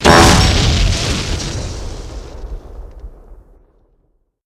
dispenser_explode.wav